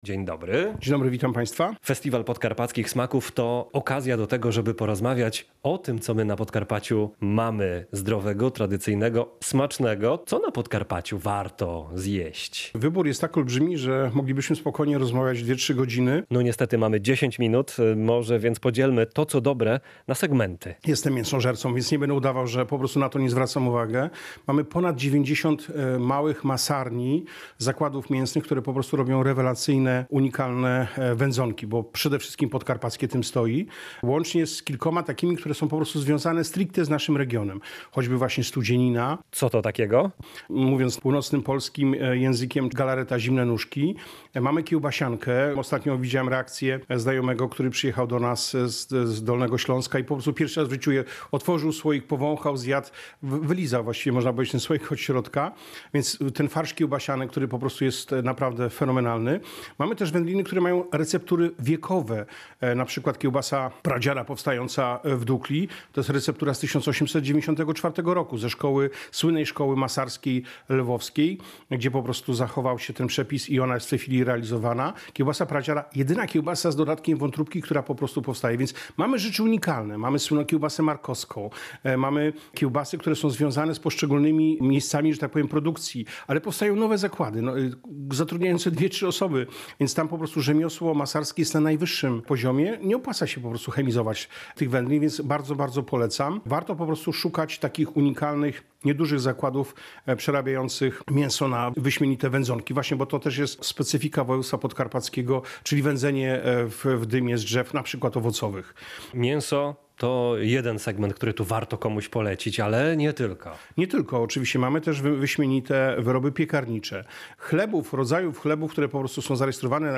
– mówi gość Polskiego Radia Rzeszów.